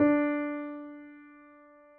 piano_050.wav